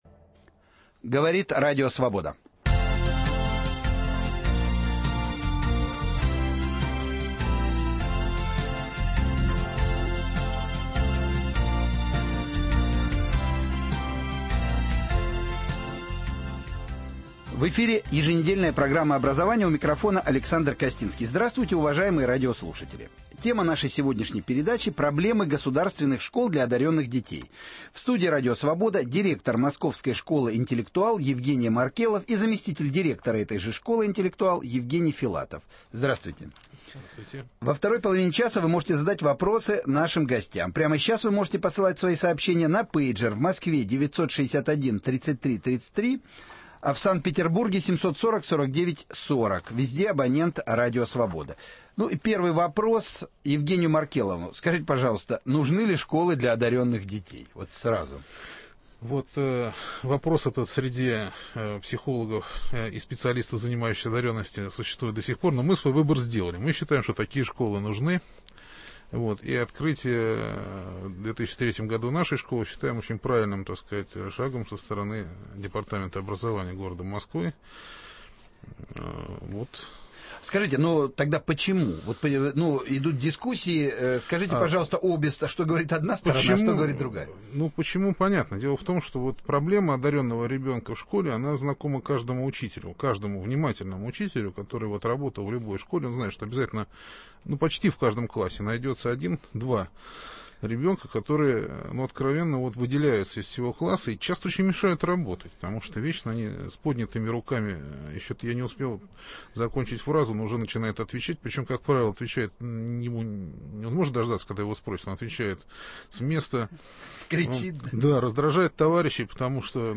Проблемы государственных школ для одаренных детей. Гость студии